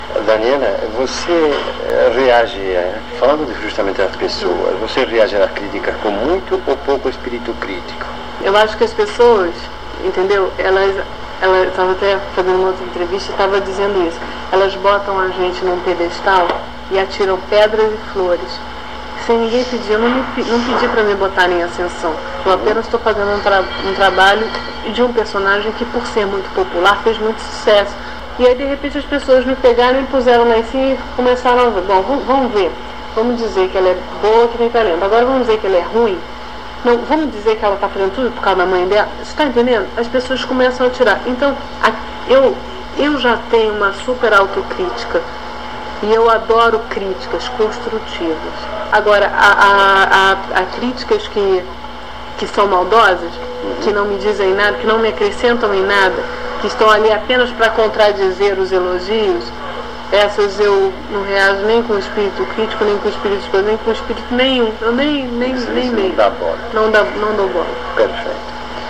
Audios -entrevista
Essa entrevista foi feita no PROJAC em dezembro de 1992, poucos dias antes do crime.